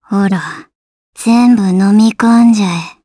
Gremory-Vox_Skill7_jp.wav